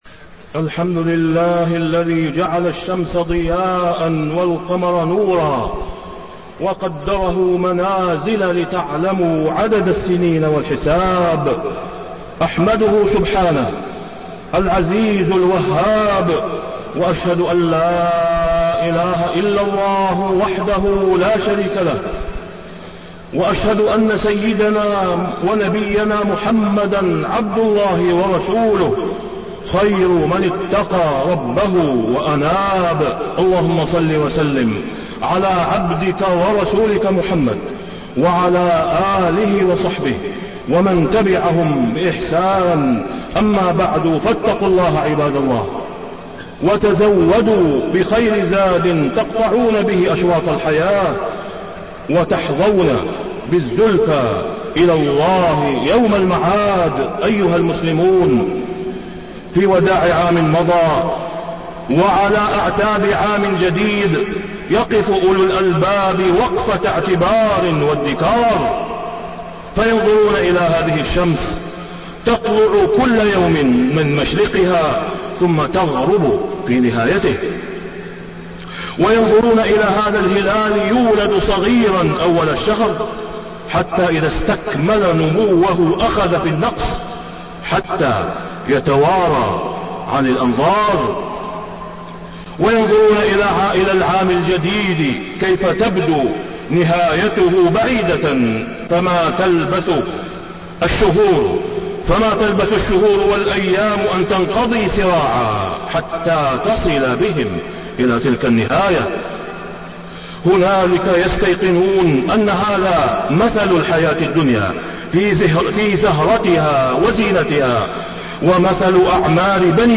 تاريخ النشر ٢٧ ذو الحجة ١٤٣١ هـ المكان: المسجد الحرام الشيخ: فضيلة الشيخ د. أسامة بن عبدالله خياط فضيلة الشيخ د. أسامة بن عبدالله خياط وداع عام واستقبال عام